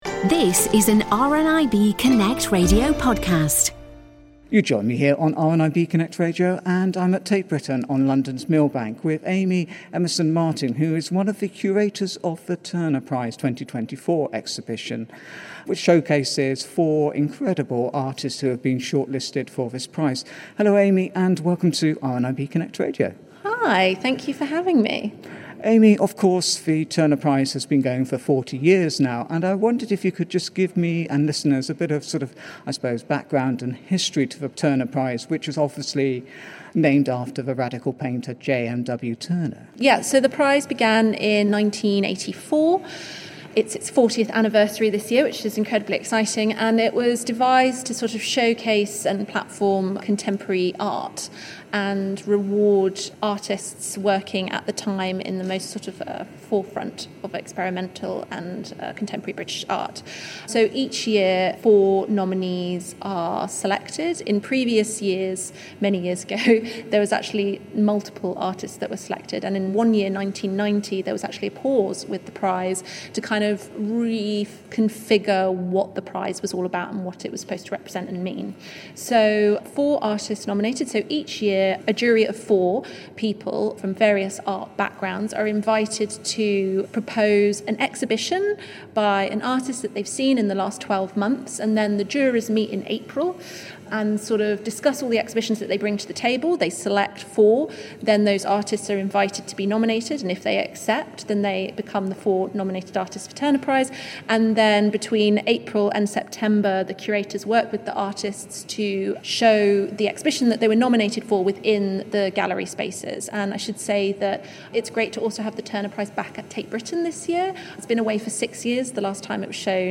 At the press view for the Turner Prize 2024 exhibition